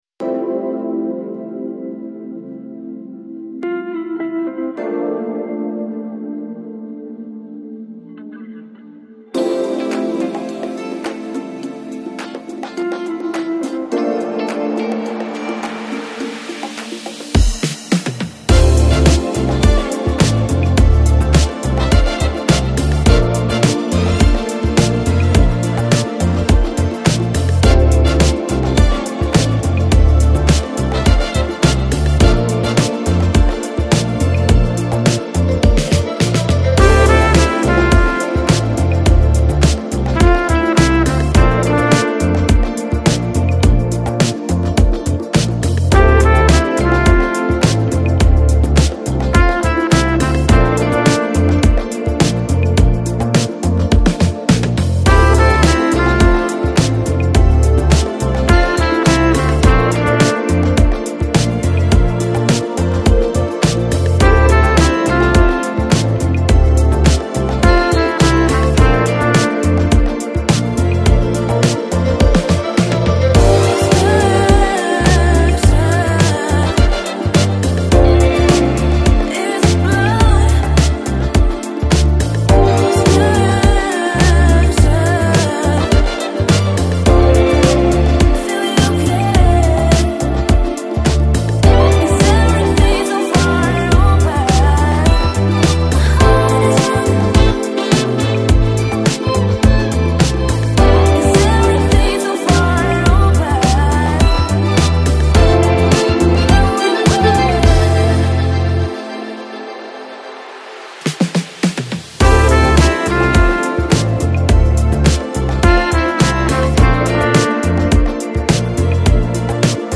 Жанр: Electronic, Lounge, Chillout, Jazz